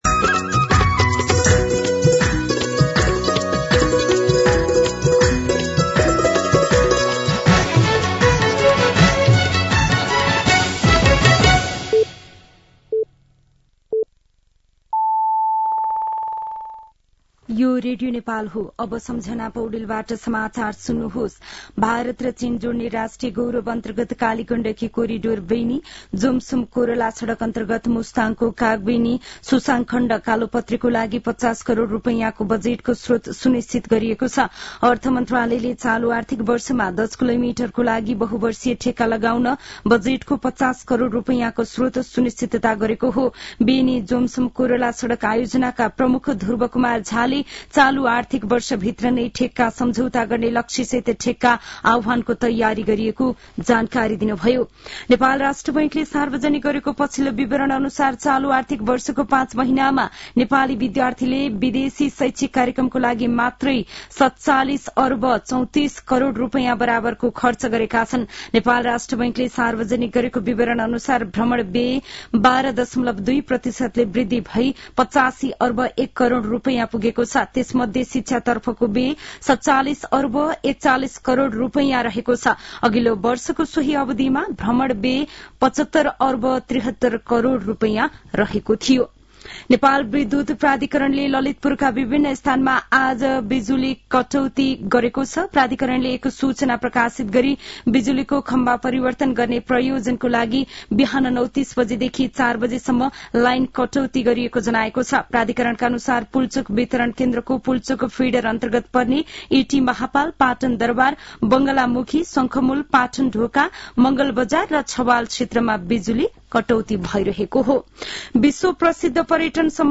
दिउँसो १ बजेको नेपाली समाचार : ५ माघ , २०८१
1-pm-Nepali-News-10-4.mp3